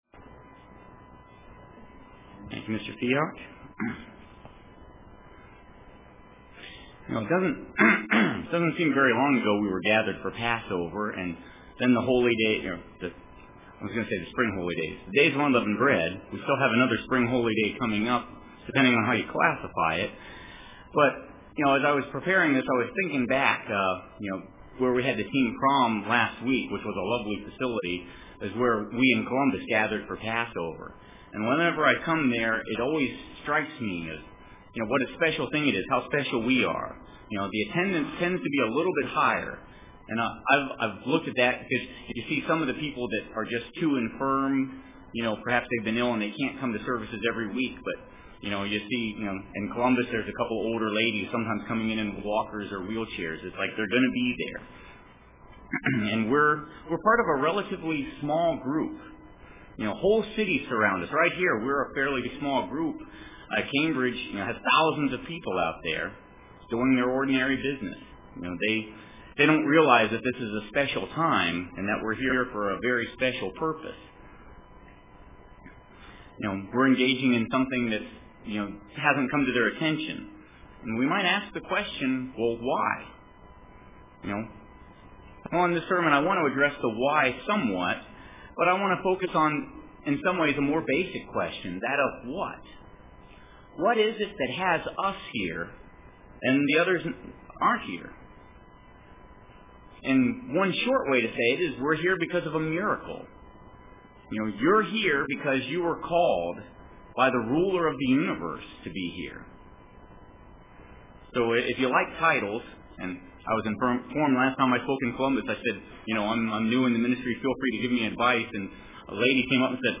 This sermon explains how great is our calling.